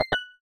Menu_Navigation03_Error.wav